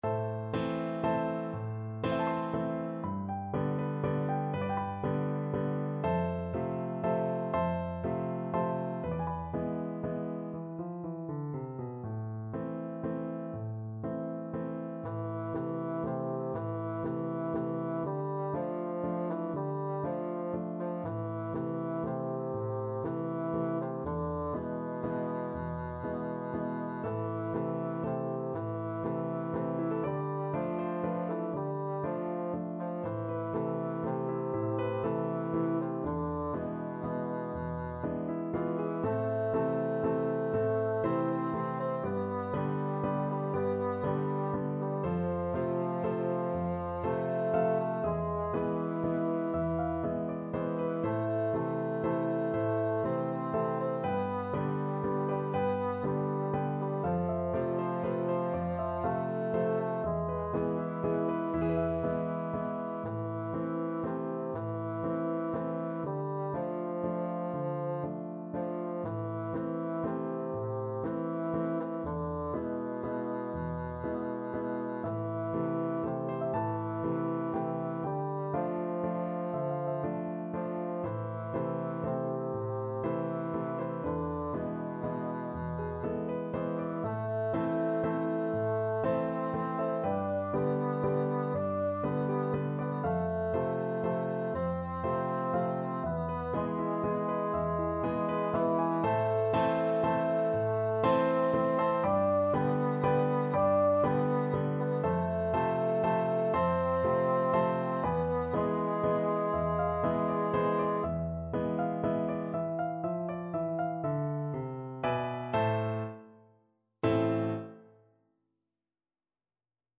Bassoon
3/4 (View more 3/4 Music)
B3-D5
A minor (Sounding Pitch) (View more A minor Music for Bassoon )
Slow Waltz .=40
Mexican